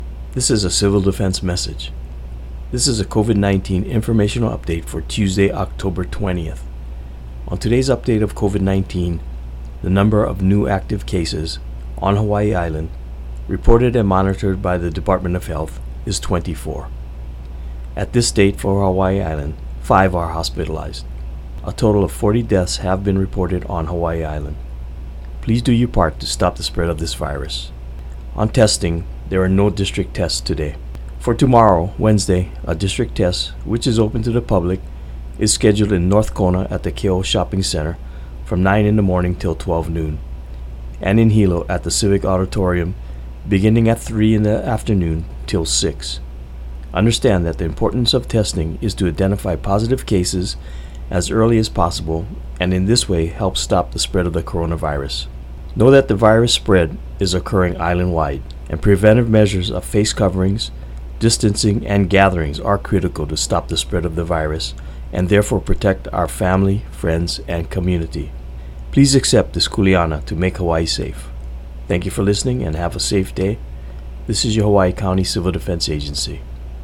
UPDATE(9:15 a.m) – From the Hawaiʻi County Civil Defense morning radio message: